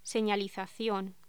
Locución: Señalización
voz